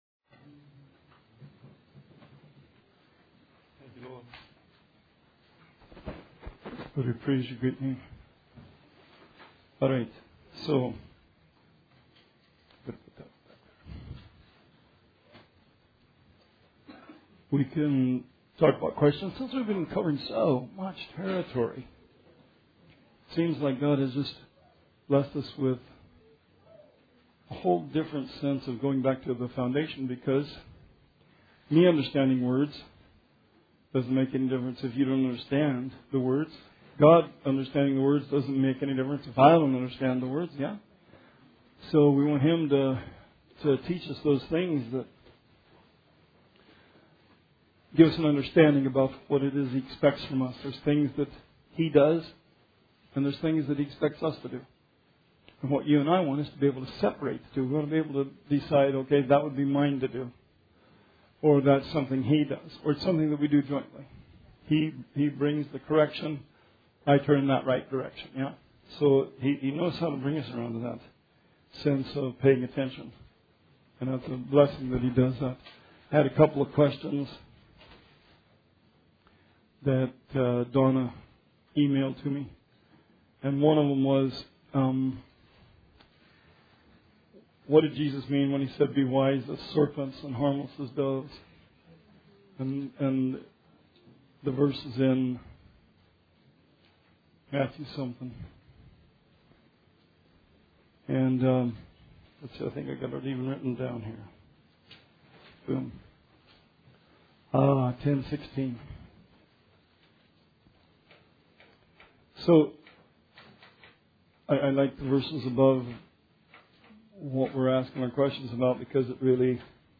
Bible Study 9/4/19